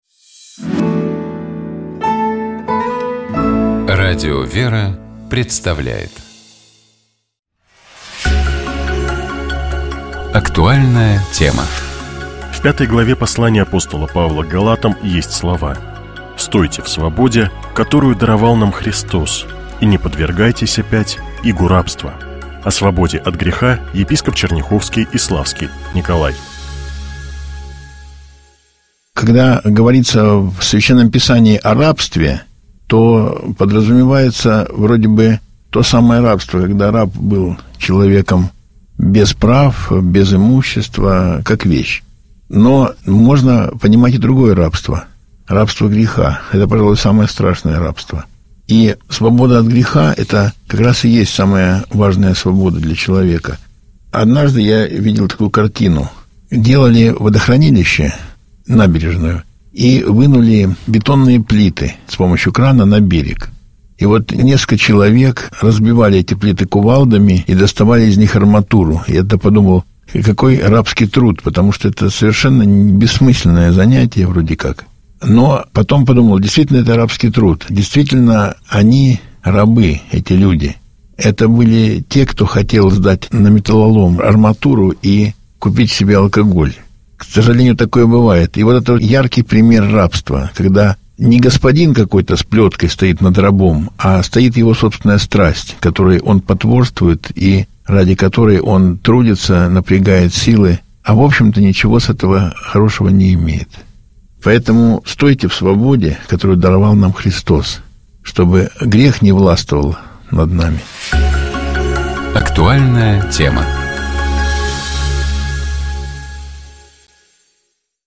О свободе от греха, — епископ Черняховский и Славский Николай.